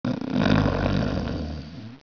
1 channel
schnarc1.wav